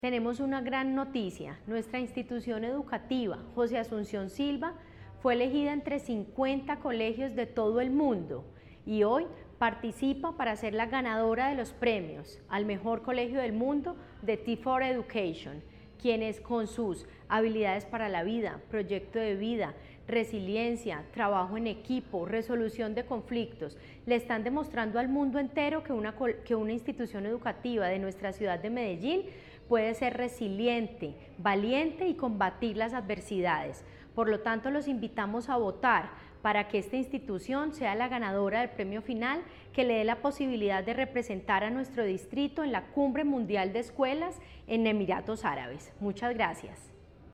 Declaraciones secretaria de Educación, Carolina Franco Giraldo